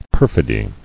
[per·fi·dy]